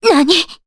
Scarlet-Vox_Damage_Jp_03.wav